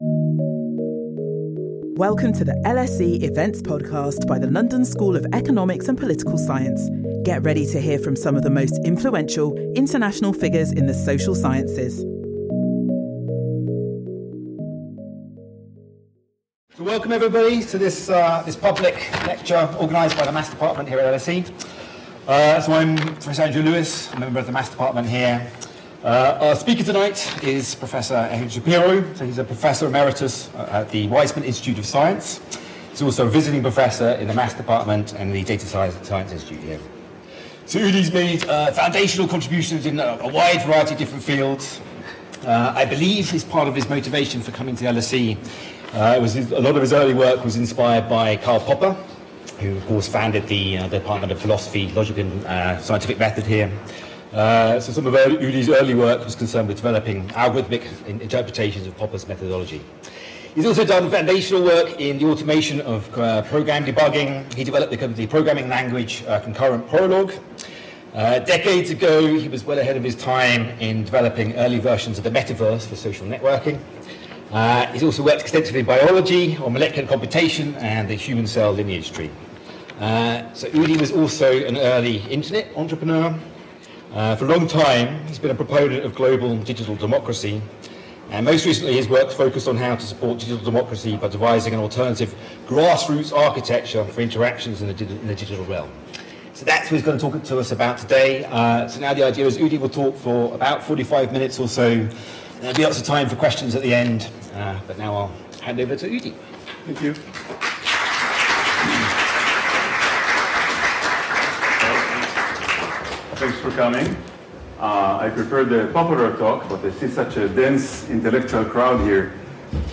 In her inaugural lecture